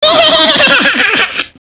gennan-laugh.wav